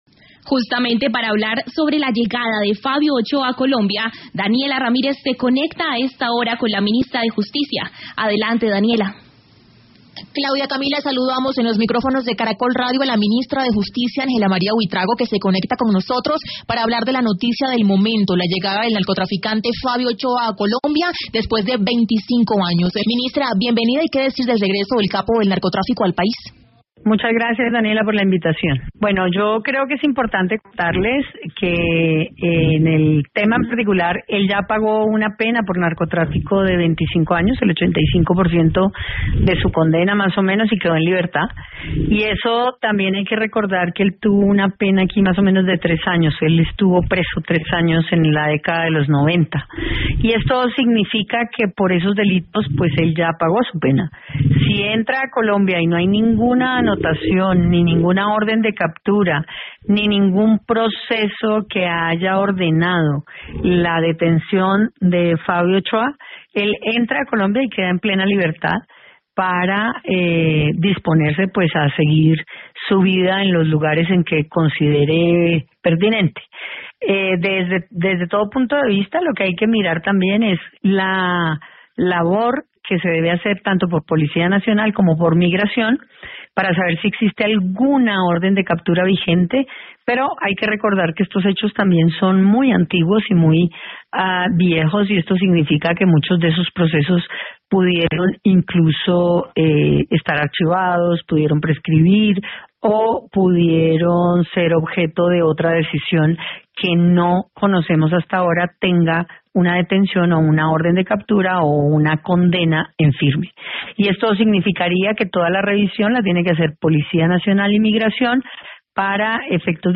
La ministra de Justicia, Ángela María Buitrago, se conectó con el noticiero de la tarde de Caracol Radio para hablar sobre la noticia del momento: la llegada del narcotraficante Fabio Ochoa a Colombia después de 25 años.